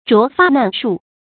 擢發難數 注音： ㄓㄨㄛˊ ㄈㄚˋ ㄣㄢˋ ㄕㄨˋ 讀音讀法： 意思解釋： 拔下的頭發難以數清（擢：拔）。比喻罪行很多。